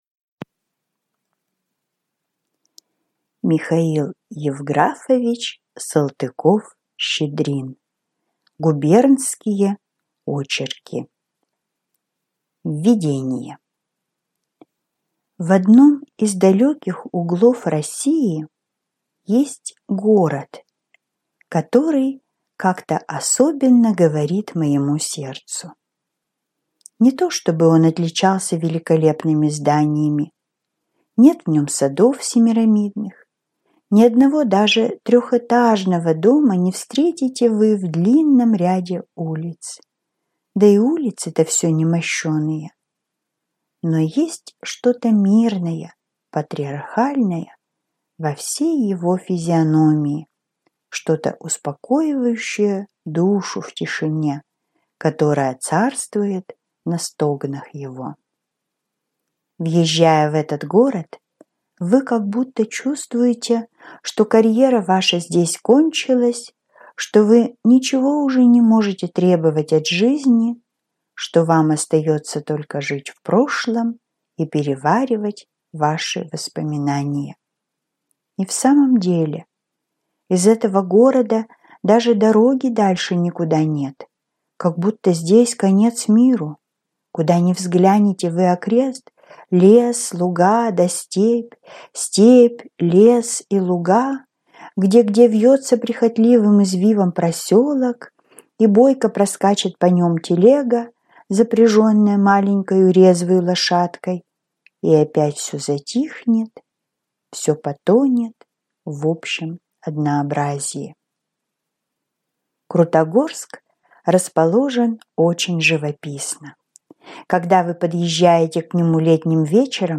Аудиокнига Губернские очерки | Библиотека аудиокниг